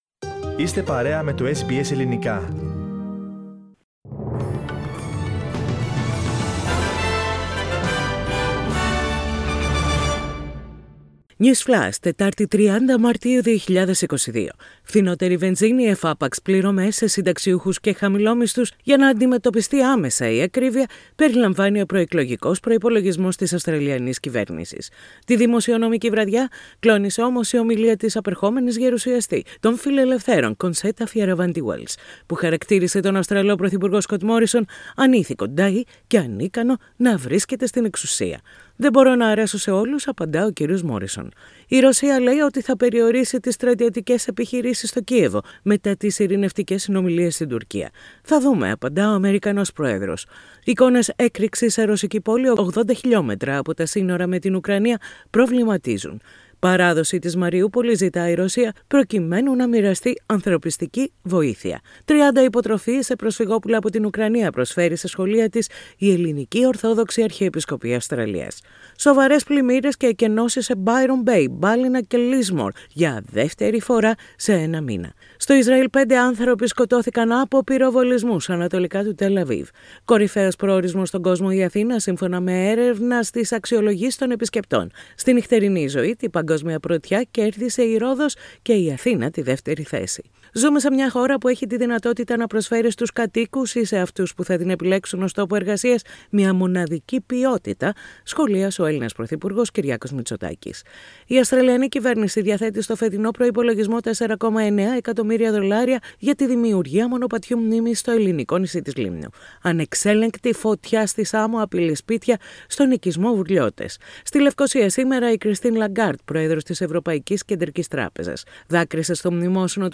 News Flash - Σύντομο Δελτίο Ειδήσεων - Τετάρτη 30.3.22